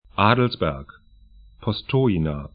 Pronunciation
Adelsberg 'a:dlsbɛrk Postojna pɔs'to:ĭna sl Stadt / town 45°47'N, 14°13'E